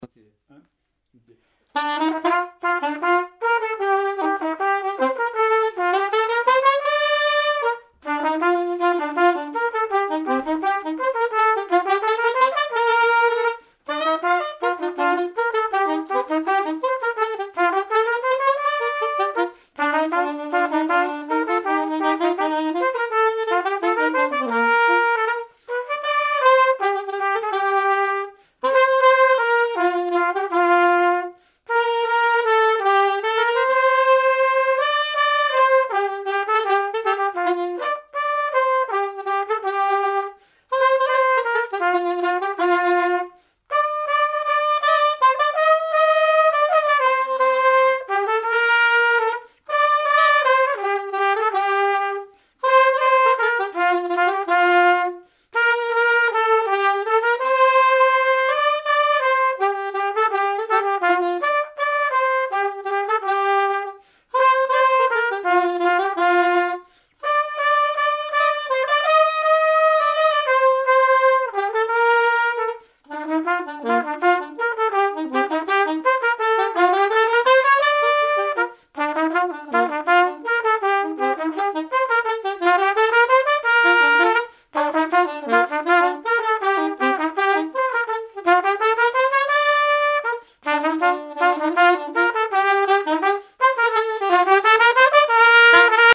circonstance : fiançaille, noce
Genre brève
Pièce musicale inédite